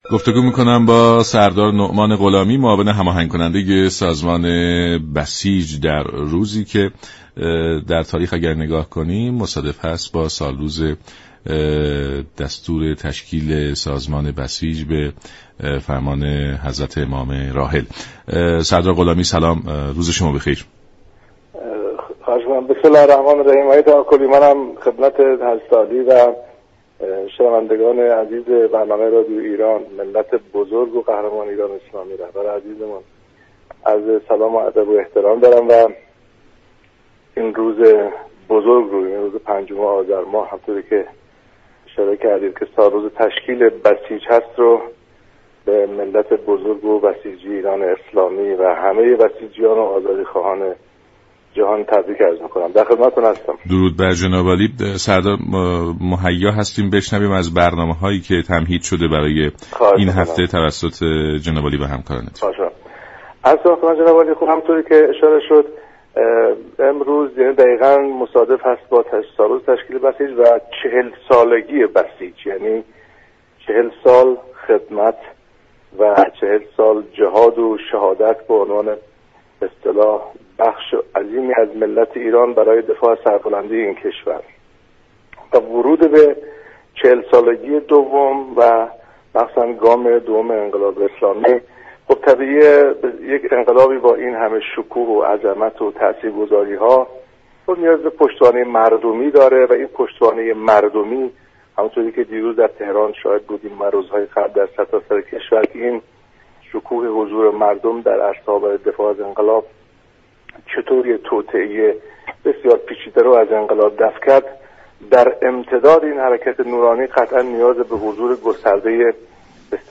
به گزارش شبكه رادیویی ایران، سردار نعمان غلامی معاون هماهنگ كننده سازمان بسیج در گفت و گو با برنامه «سلام صبح بخیر» رادیو ایران ضمن تبریك چهلمین سالگرد تاسیس بسیج، گفت: سازمان بسیج مستضعفین همچون سال های گذشته، در 162 هزار رده مقاومت، نقش مردم را در چهل سال ایستادگی تببین می كند.